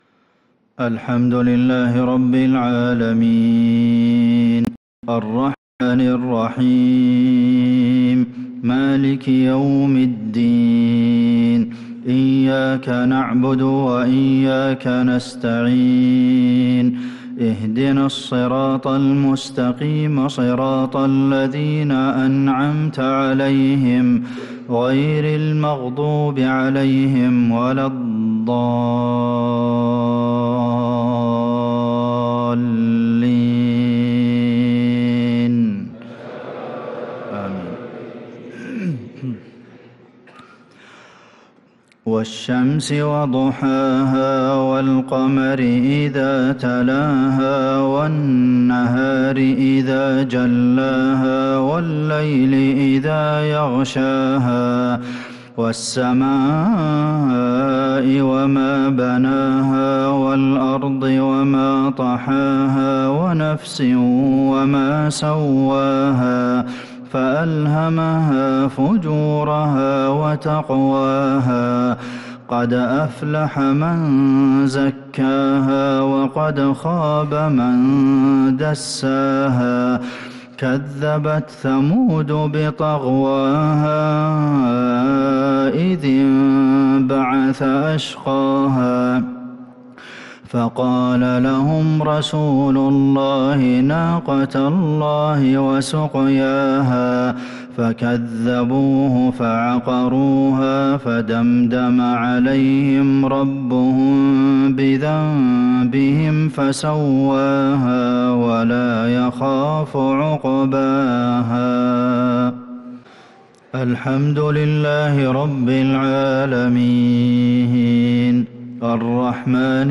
صلاة المغرب ٨-٨-١٤٤٦هـ | سورتي الشمس و الماعون كاملة | Maghrib prayer from Surah ash-Shams & al-Ma`un |7-2-2025 > 1446 🕌 > الفروض - تلاوات الحرمين